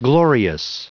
Prononciation du mot glorious en anglais (fichier audio)
Prononciation du mot : glorious